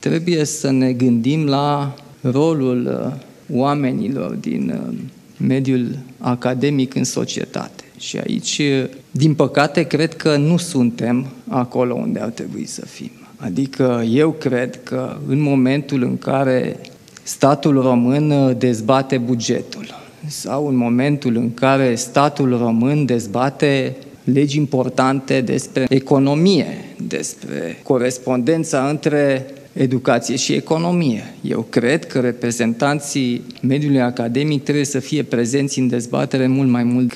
Mediul academic trebuie să aibă un rol mult mai important în luarea deciziilor, chiar şi a celor de la nivel guvernamental, a arătat preşedintele Nicuşor Dan, la Iaşi, unde a participat la ceremonia organizată cu prilejul împlinirii a 165 de ani de la înfiinţarea Universităţii „Alexandru Ioan Cuza” şi a Universităţii de Arte „George Enescu”.